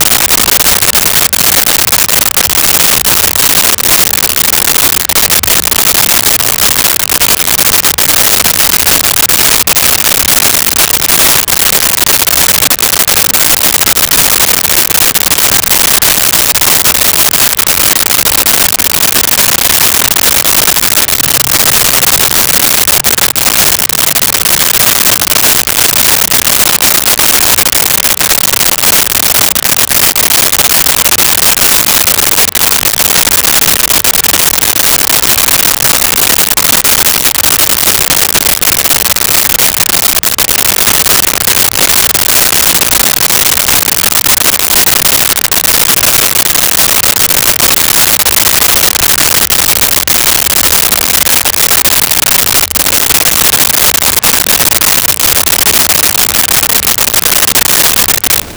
Rain Car Interior
Rain Car Interior.wav